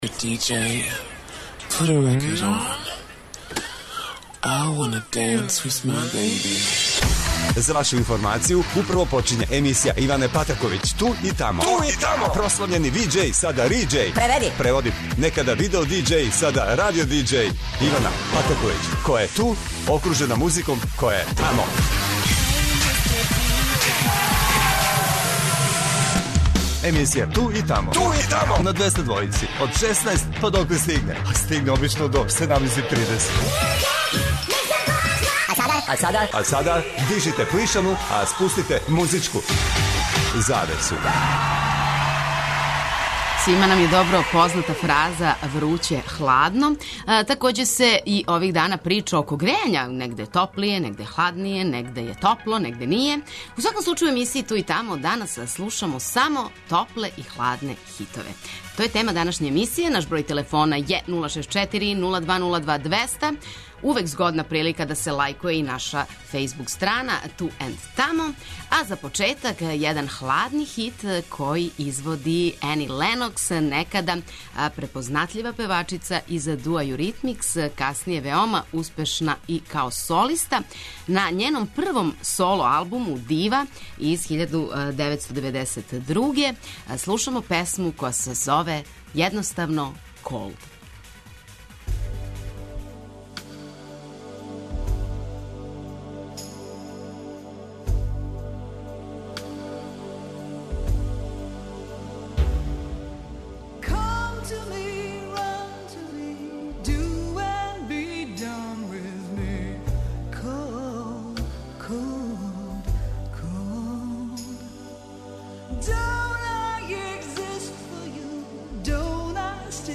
преузми : 42.16 MB Ту и тамо Autor: Београд 202 Емисија Ту и тамо суботом од 16.00 доноси нове, занимљиве и распеване музичке теме. Очекују вас велики хитови, страни и домаћи, стари и нови, супер сарадње, песме из филмова, дуети и још много тога.